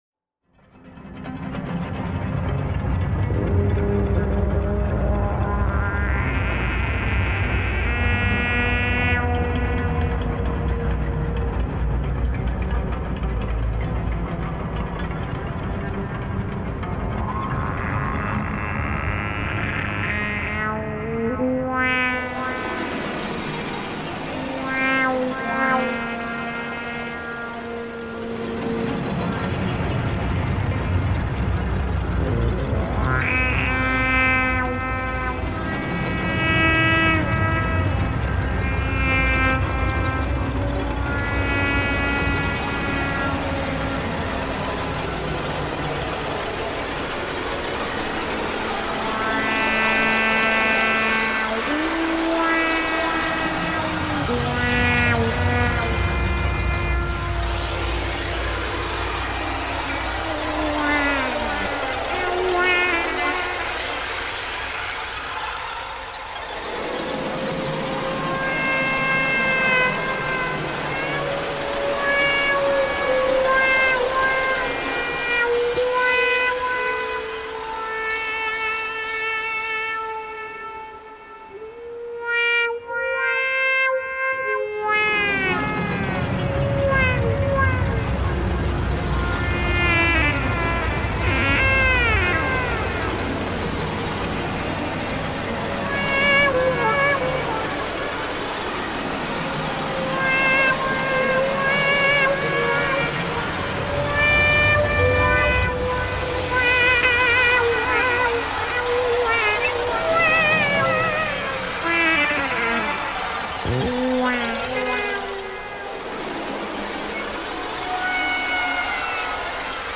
(electronics)
(electric violin)
File: JAZZ Improvisation Electric